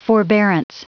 Prononciation du mot forbearance en anglais (fichier audio)
Prononciation du mot : forbearance